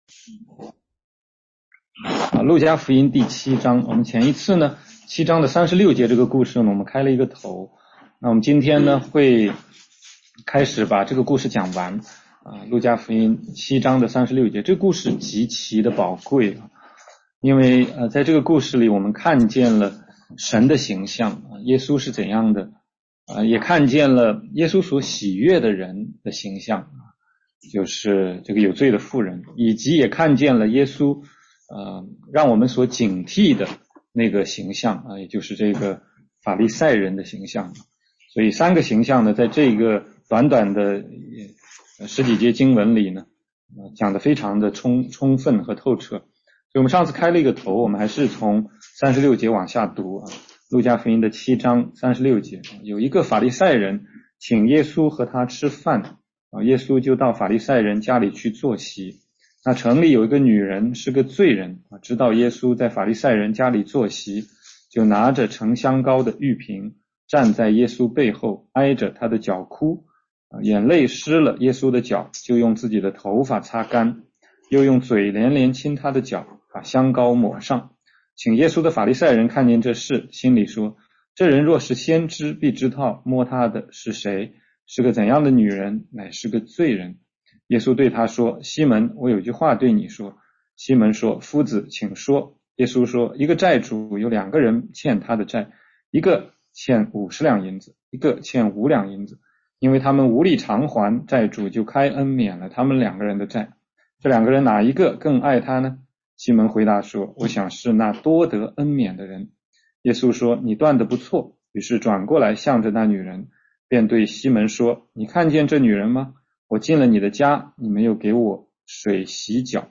16街讲道录音 - 路加福音7章36-50节：有罪的女人和法利赛人西门
全中文查经